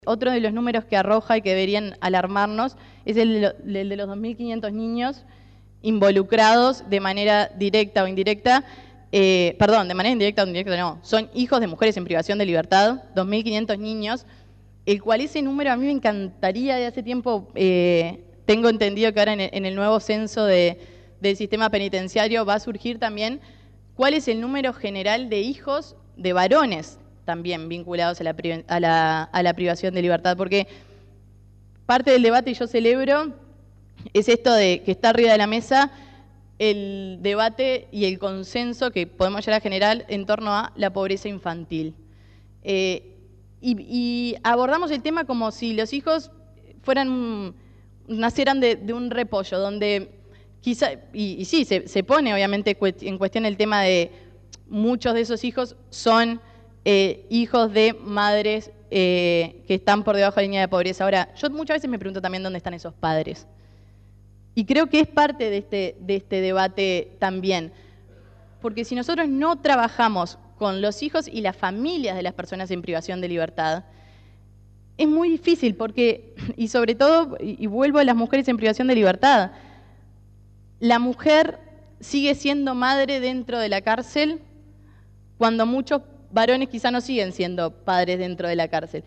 En la presentación de este informe, hicieron uso de la palabra diferentes actores políticos, tanto del gobierno como legisladores del oficialismo y de la oposición.